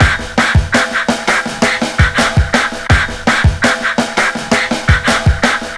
Index of /90_sSampleCDs/Best Service ProSamples vol.40 - Breakbeat 2 [AKAI] 1CD/Partition A/WEIRDBEAT083